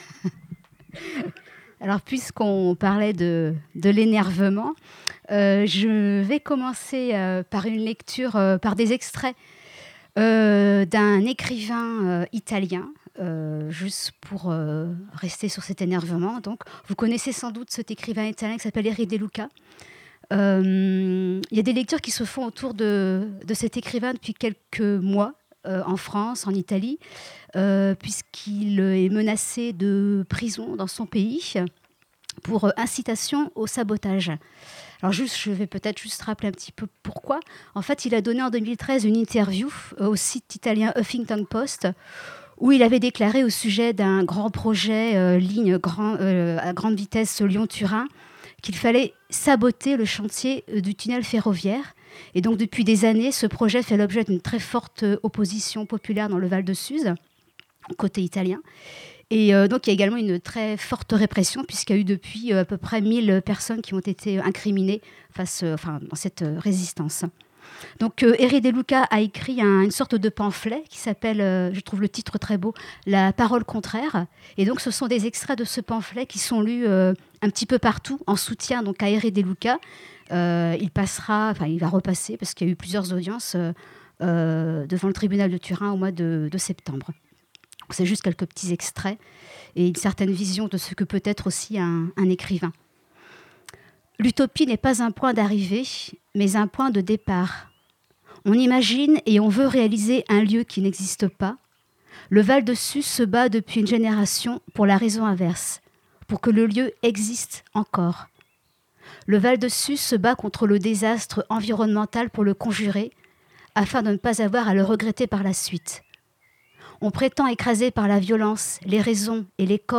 lecture en soutien à Erri de Luca.
Une lecture à laquelle elle ajouta celle de ses propres poèmes tirés de sa présence lors d’audiences et à l’écoute de réquisitions diverses.